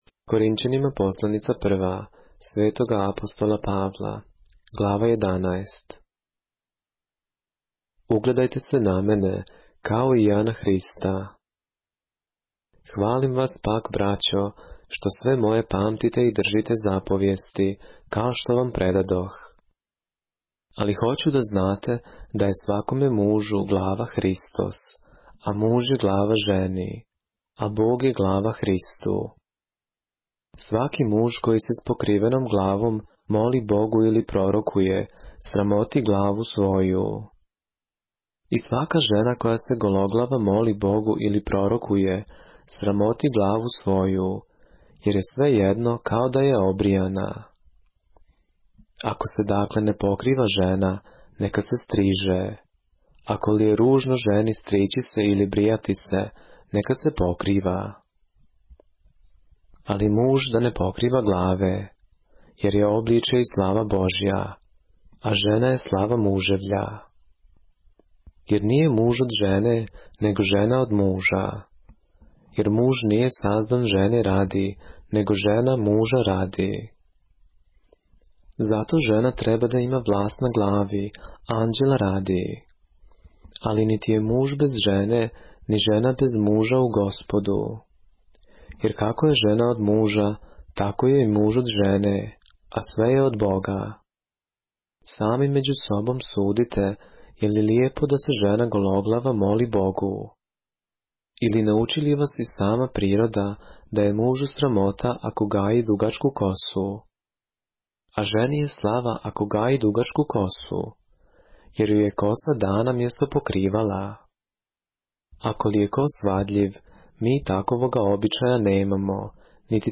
поглавље српске Библије - са аудио нарације - 1 Corinthians, chapter 11 of the Holy Bible in the Serbian language